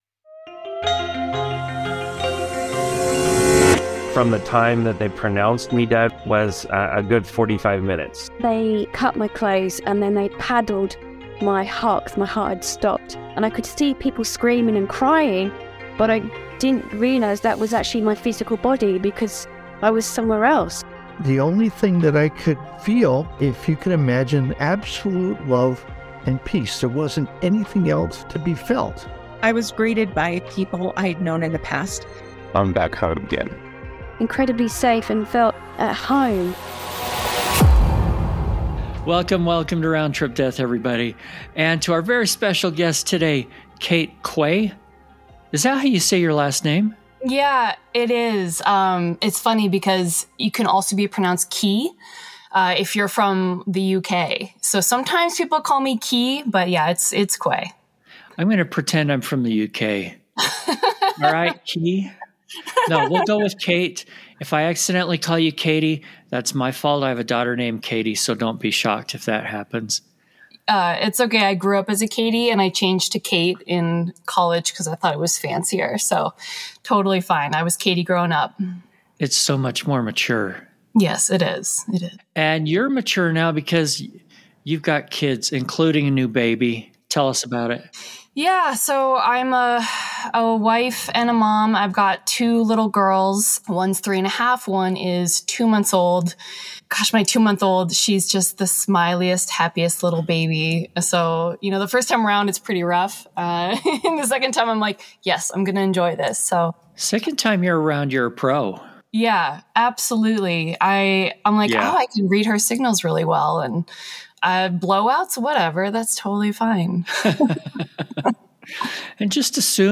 Round Trip Death podcast features discussions with people who have actually died, visited the other side, and returned to talk about it.